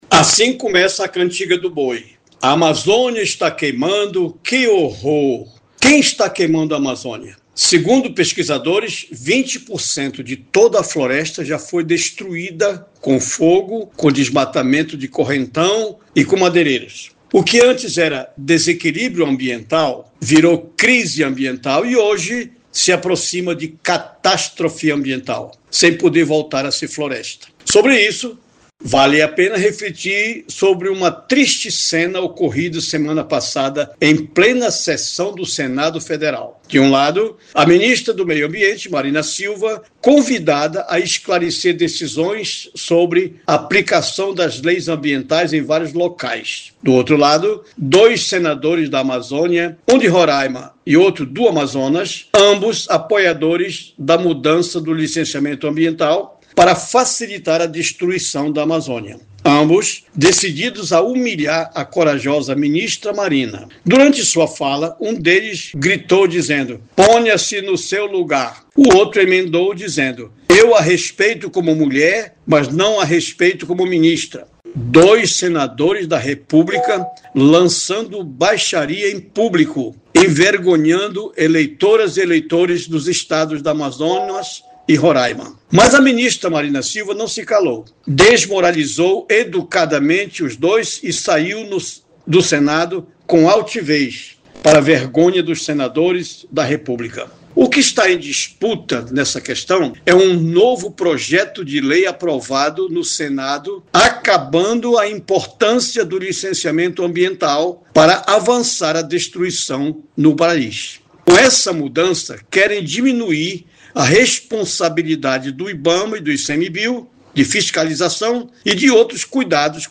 Neste editorial